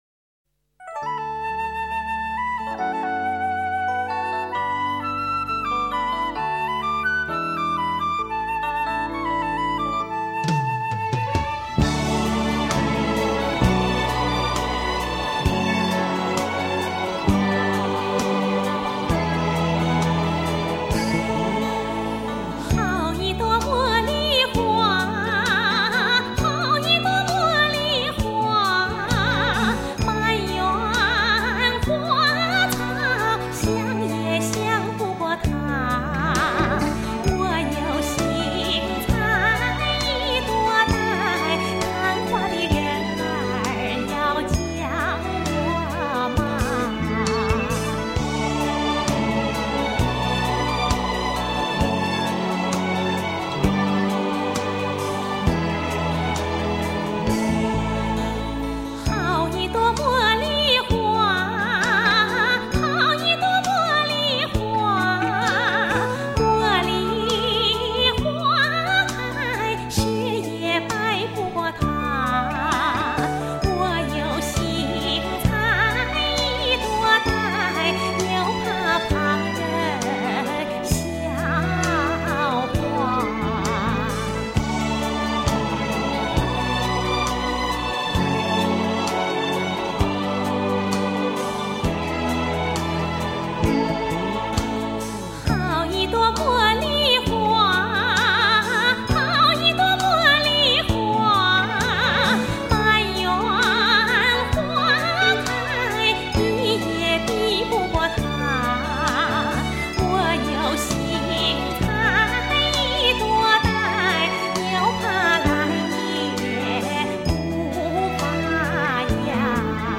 透过这质朴而迷人的旋律，感受中华民族的笑颜，